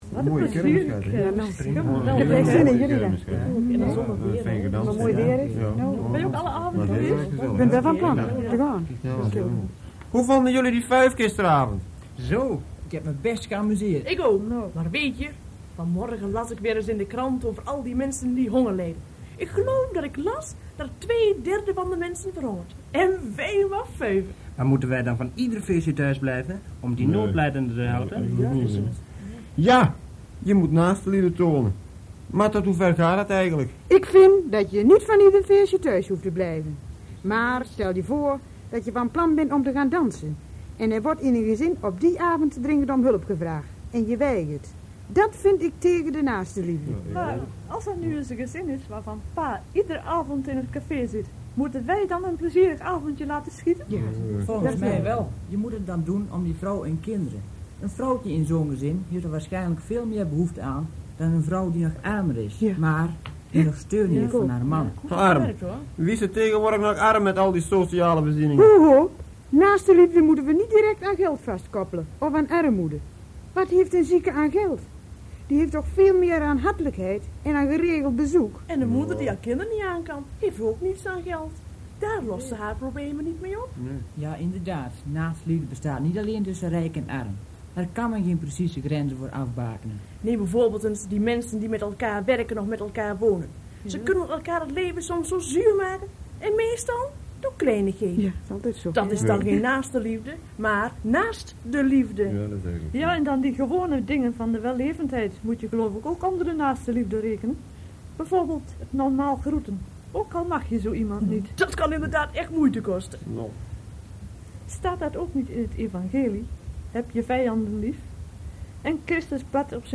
Onlangs dook een opname op van het KRO programma Picolo uit 1959.